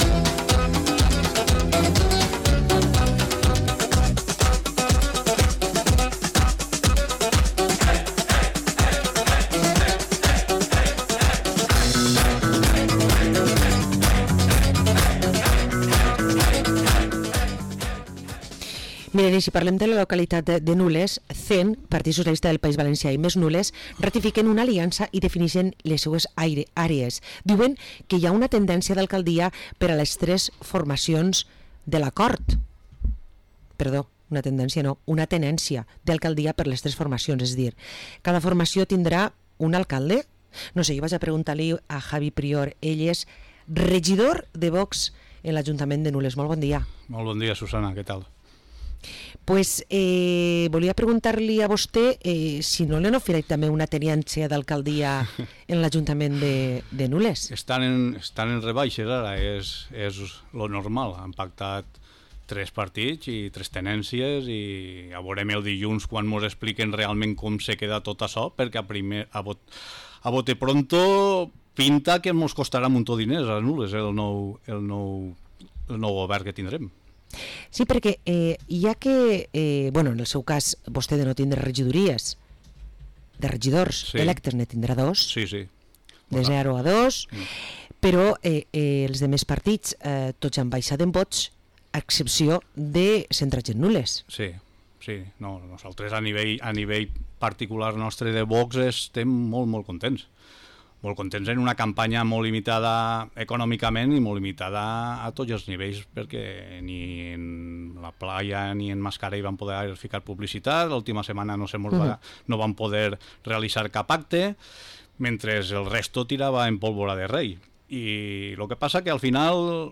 Entrevista a Javi Prior, regidor de VOX a l´Ajuntament de Nules